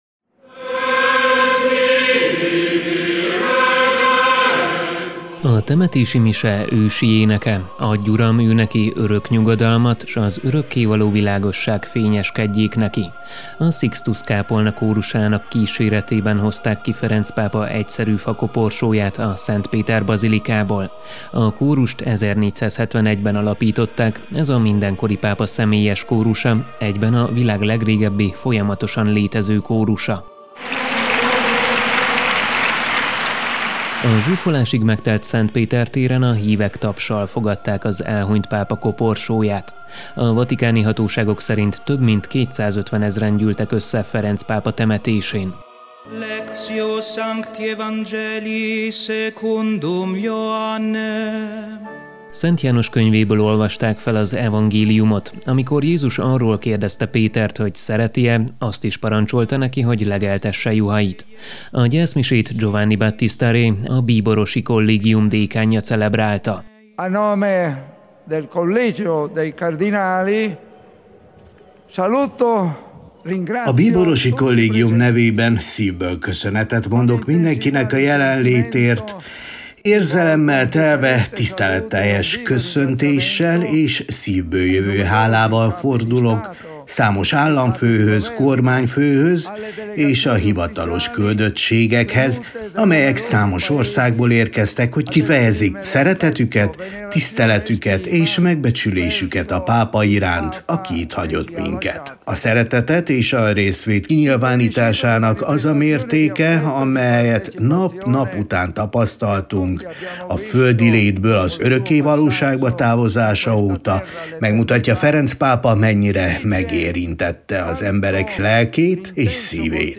A Sixtus-kápolna kórusának éneke kíséretében hozták ki Ferenc pápa egyszerű fakoporsóját a Szent Péter bazilikából.
A zsúfolásig megtelt Szent Péter téren a hívek tapssal fogadták az elhunyt pápa koporsóját.
Nagy taps volt a Szent Péter téren, amikor Giovanni Battista Re azokra a gesztusokra emlékeztetett, amelyeket Ferenc pápa a menekültek érdekében tett.
A gyászszertartás után harangzúgás kíséretében vitték vissza Ferenc pápa földi maradványait a Szent Péter bazilikába.